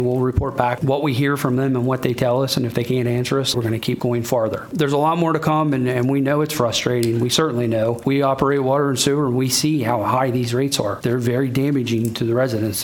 Allegany County Commissioners heard the concerns from a room full Bel Air residents regarding the cost and quality of their utilities during last night’s meeting.  County Administrator Jason Bennett said the commissioners aren’t able to just take over Bel Air’s water service, but they have a meeting planned with the new owner, American Water Works Company…